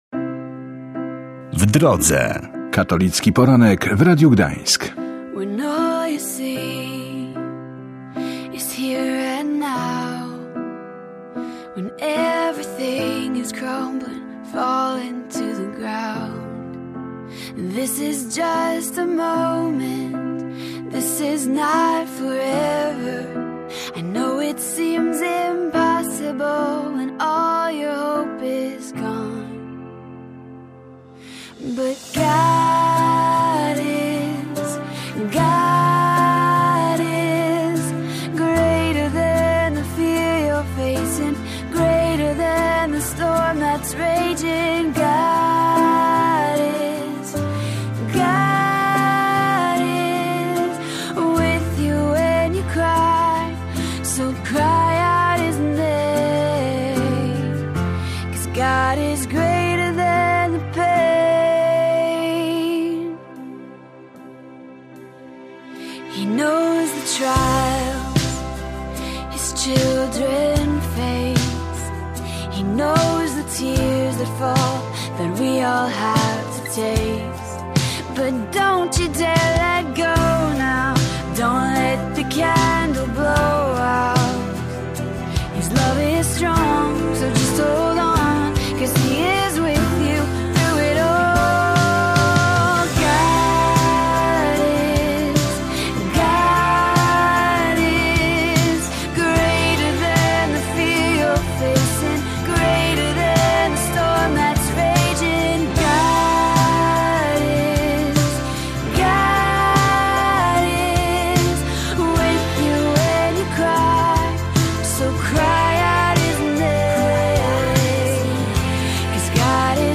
Pola Nadziei i rozmowa ze Sławojem Leszkiem Głódziem